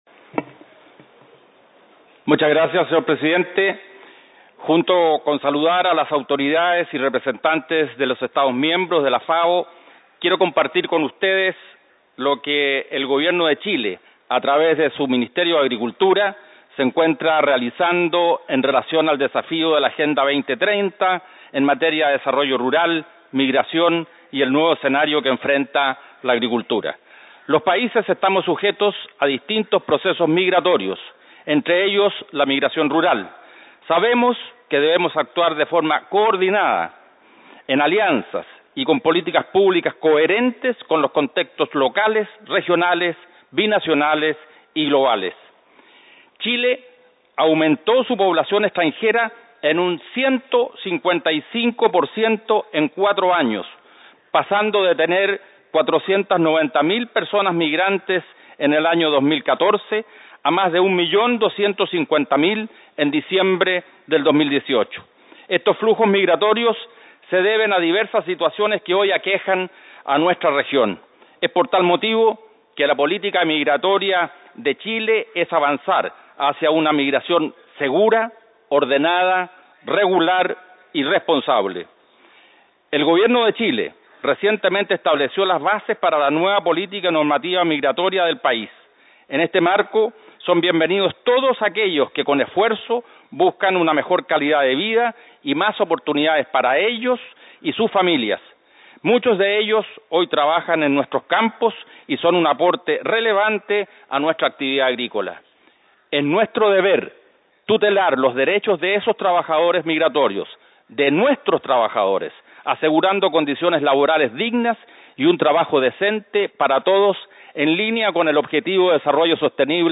Addresses and Statements
Excmo. Sr. Don Alfonso Vargas Subsecretario de Agricultura de la República de Chile